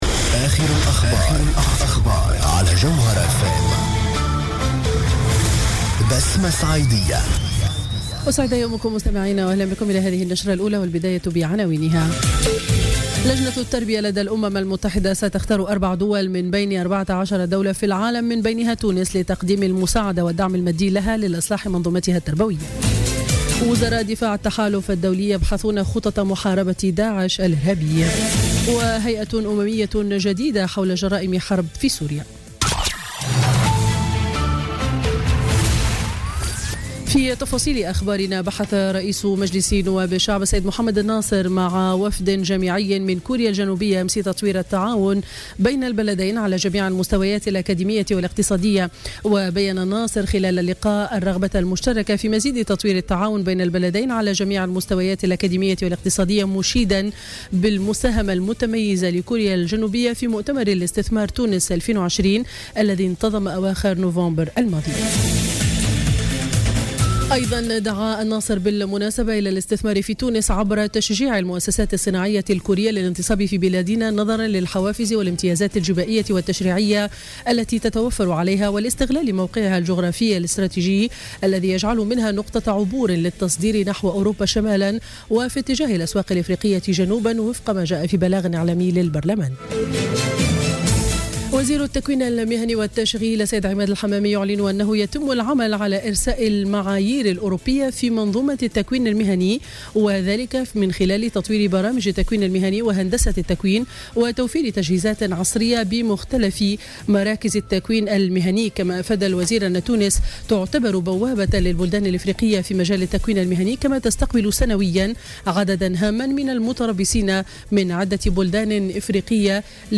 نشرة أخبار السابعة صباحا ليوم الجمعة 17 فيفري 2017